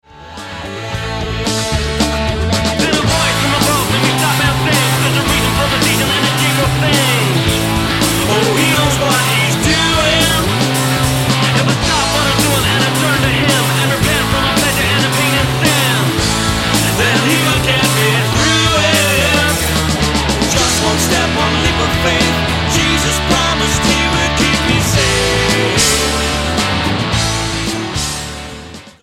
Irish rock team
Style: Rock